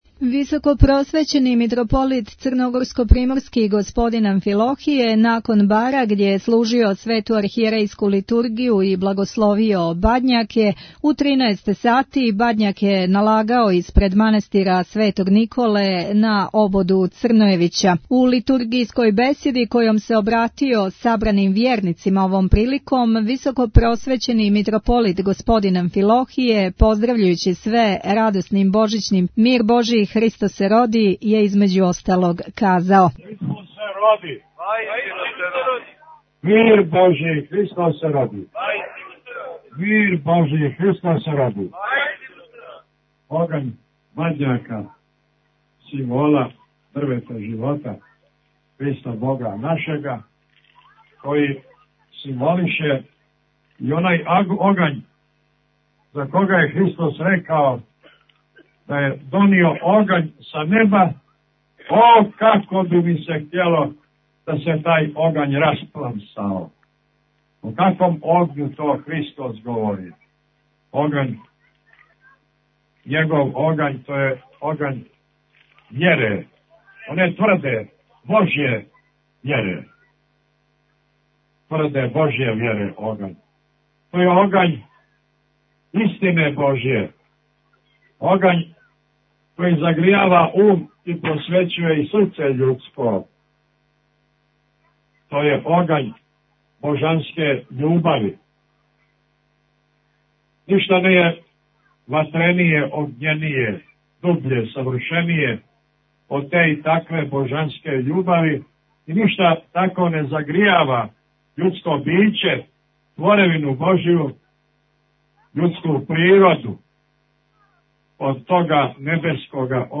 Аудио :: Browse by :: title Audio by title mitropolit_obod_crnojevica Митрополит Амфилохије благословио и налагао бадњаке на Ободу Црнојевића Tagged: Извјештаји 9:49 минута (1.69 МБ) Преузмите аудио датотеку 1849 преузимања 27 слушања